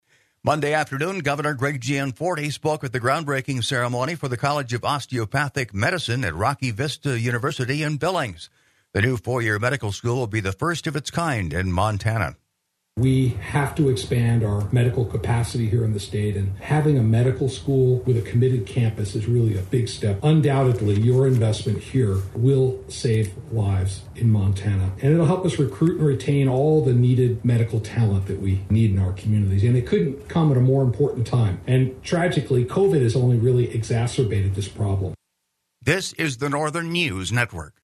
Monday afternoon, Governor Greg Gianforte spoke at the groundbreaking ceremony for the College of Osteopathic Medicine at Rocky Vista University in Billings. The new four-year medical school will be the first of its kind in Montana.